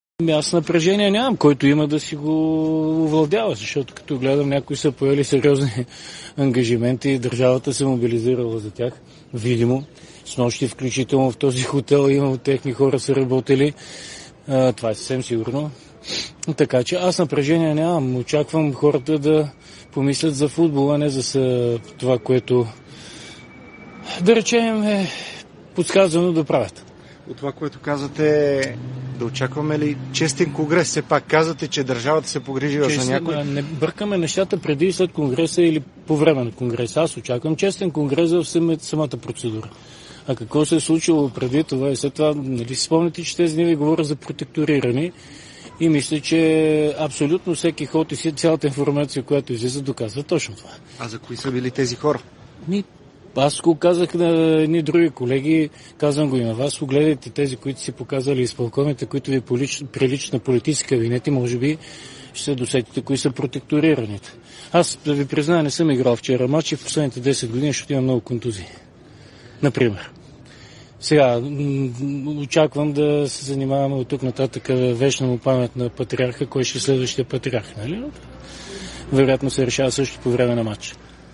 говори пред хотел „Маринела“, където ще се проведе Конгреса на БФС.